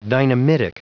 Prononciation du mot dynamitic en anglais (fichier audio)
Prononciation du mot : dynamitic